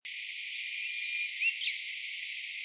強腳樹鶯 Cettia fortipes robusticeps
錄音地點 南投縣 鹿谷鄉 杉林溪
錄音環境 溪邊灌木叢
行為描述 鳴唱
收音: 廠牌 Sennheiser 型號 ME 67